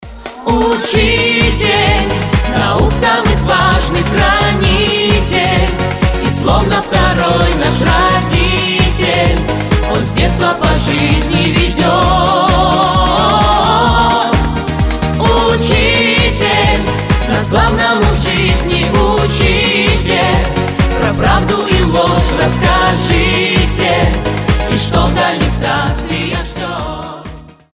• Качество: 320, Stereo
добрые
мужской и женский вокал